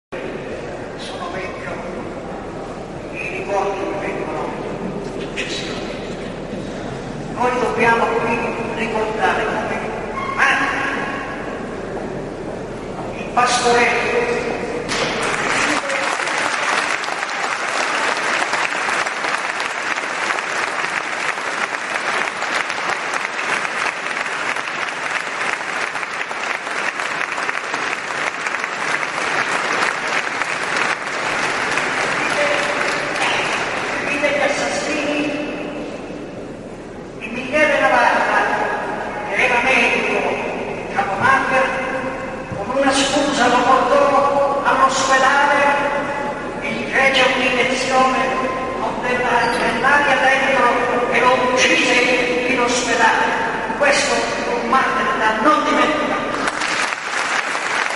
Guarda il video sen. Emanuele Macaluso 1' 00'' 454 Scarica mp3